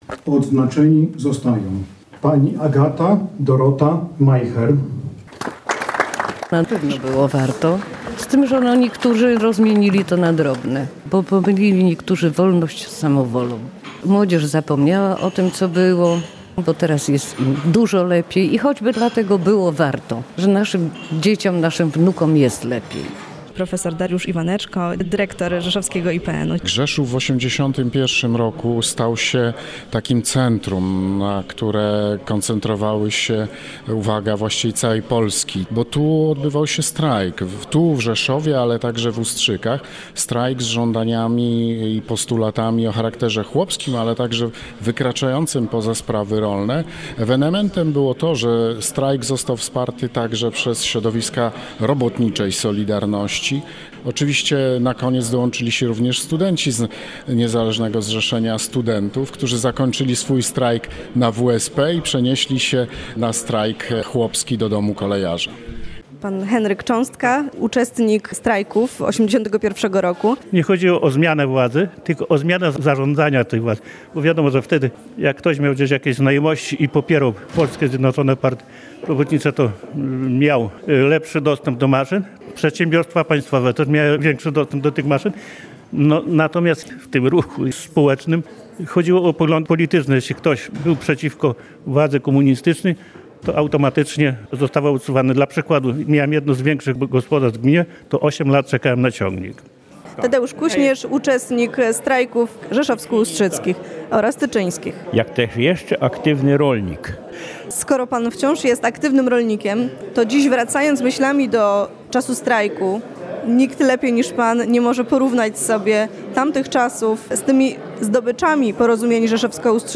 Wiadomości • W Wojewódzkim Domu Kultury w Rzeszowie odbyły się obchody 45-lecia strajków i podpisania Porozumień Rzeszowsko-Ustrzyckich, powstania NSZZ Rolników Indywidualnych „Solidarność” oraz rejestracji Niezależnego Zrzeszenia Studentów. Uroczystości zgromadziły uczestników tamtych wydarzeń, przedstawicieli władz i mieszkańców regionu.